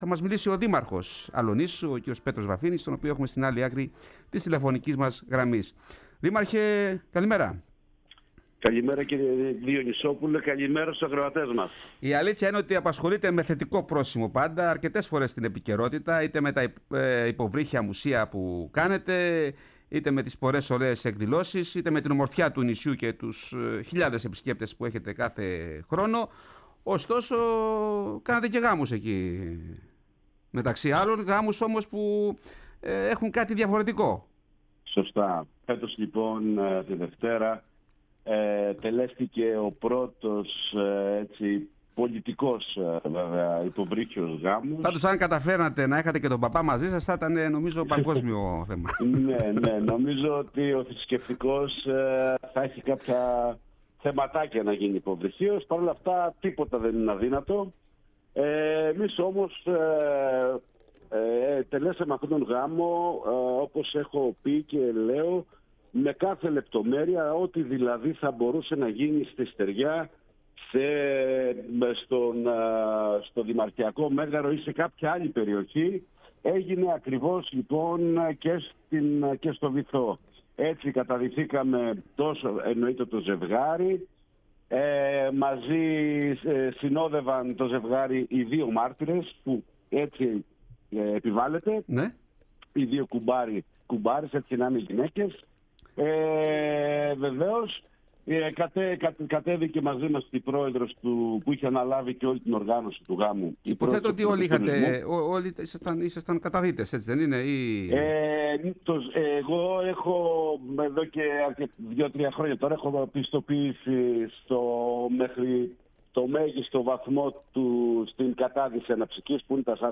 φιλοξενήθηκε σήμερα ο δήμαρχος Αλοννήσου, Πέτρος Βαφίνης.